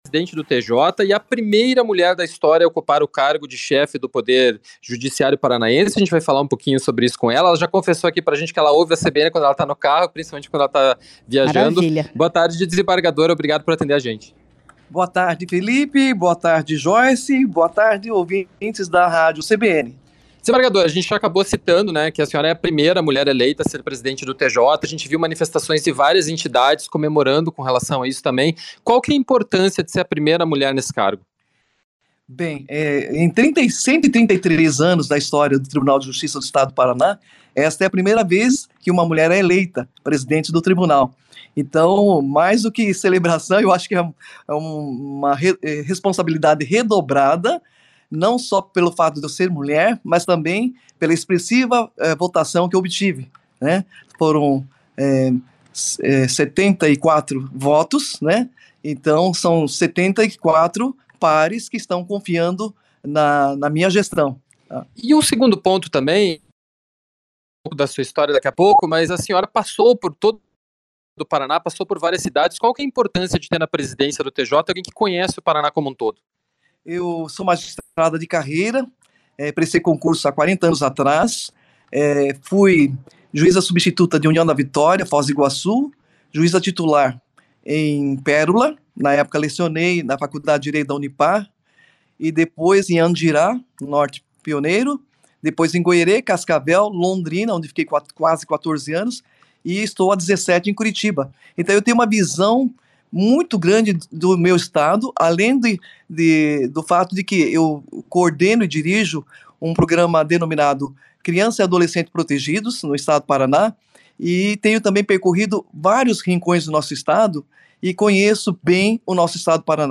A CBN Curitiba foi até ao Tribunal de Justiça do Estado do Paraná para conversar com a desembargadora Lidia Maejima. Ela foi eleita presidente do TJ e é a primeira mulher da história a ocupar o cargo de chefe do Poder Judiciário paranaense.